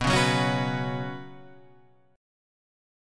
B Suspended 2nd